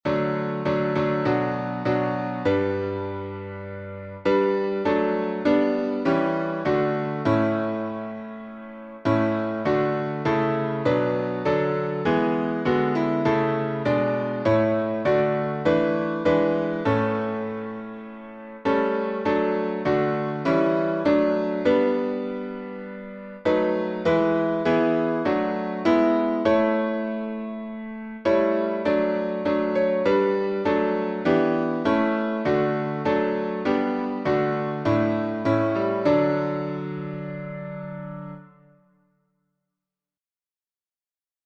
#51: Crown Him with Many Crowns — D major | Mobile Hymns